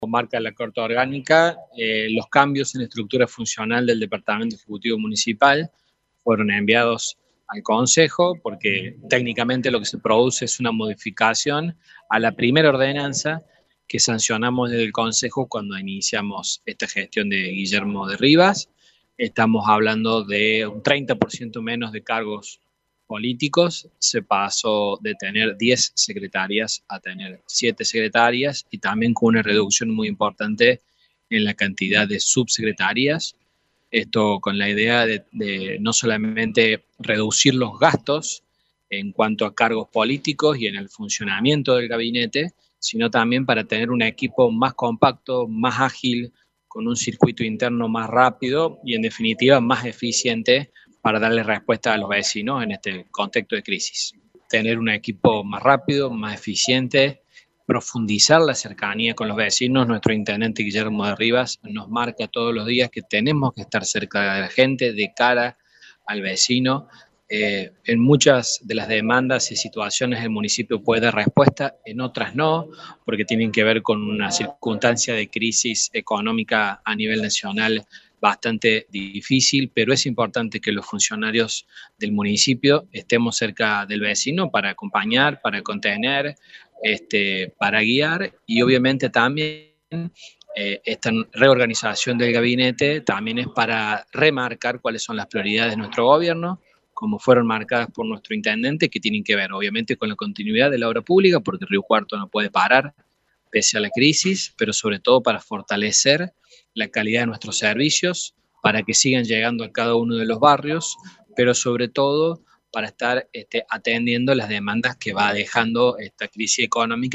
Leandro Carpintero, presidente del bloque del oficialismo en el concejo, explicó que la Carta Orgánica Municipal ordena que estos cambios sean aprobados por el poder legislativo local.